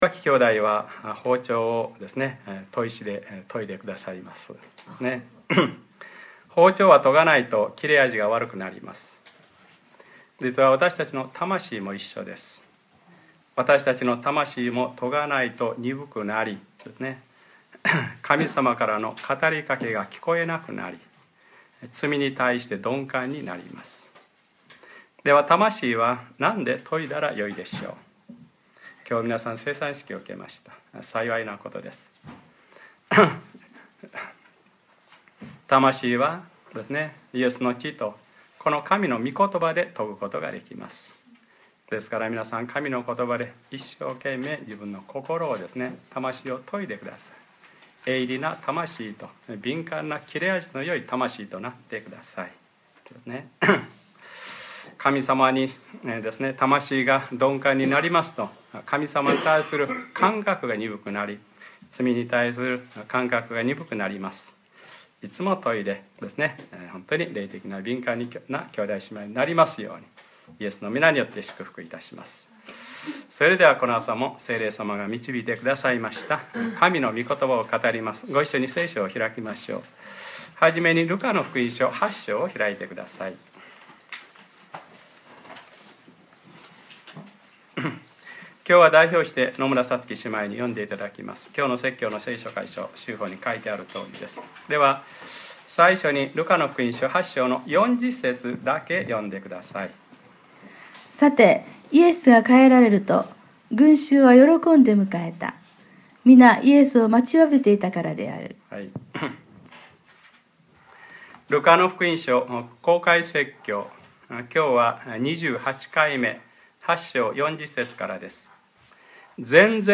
2013年11月03日(日）礼拝説教 『ルカｰ２８ 恐れないでただ信じなさい』